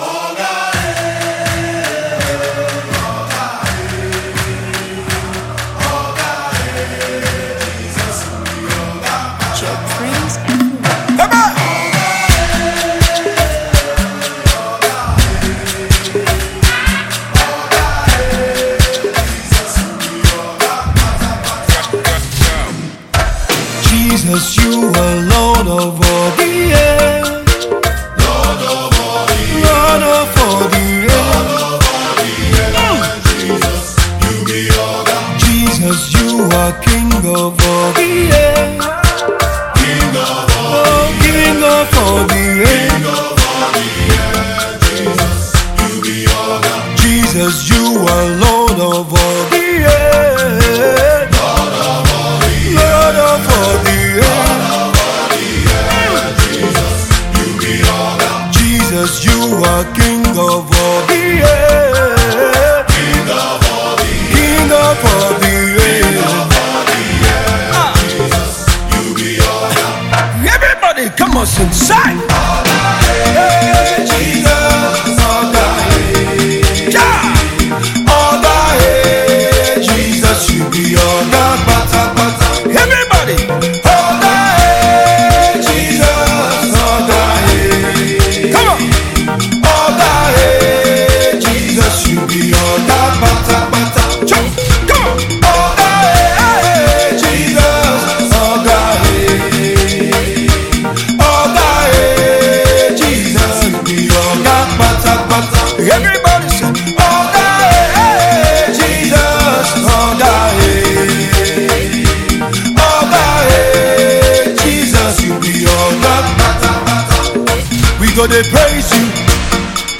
danceable song